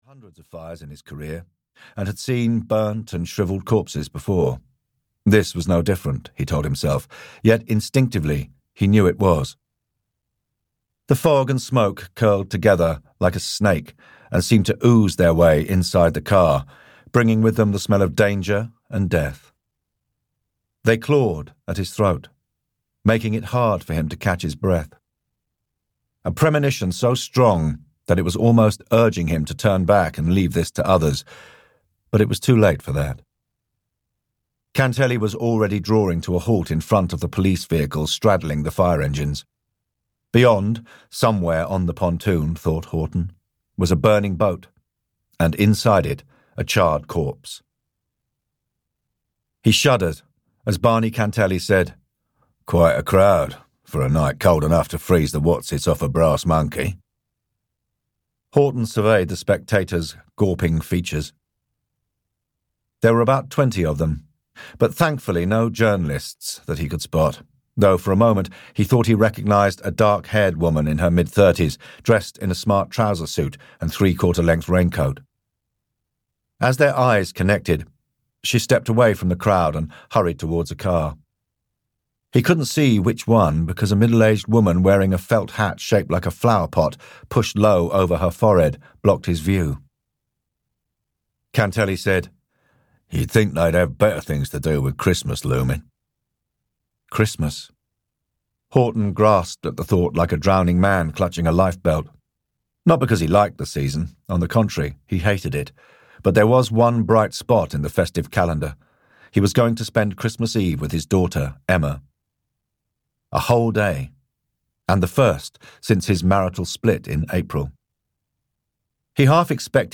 The Horsea Marina Murders (EN) audiokniha
Ukázka z knihy